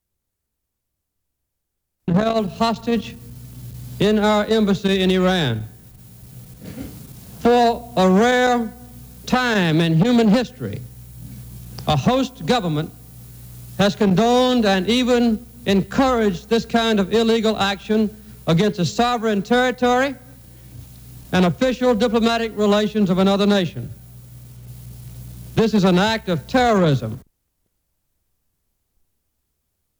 U.S. President Jimmy Carter calls the actions of the Iranian government "terrorism" on the 12th day that 60 Americans are held hostage in the U.S. Embassy in Tehran, Iran.
Subjects Iran Hostage Crisis (1979-1981) Diplomatic relations Iran United States Material Type Sound recordings Language English Extent 00:00:30 Venue Note Broadcast on NPR, November 15, 1979.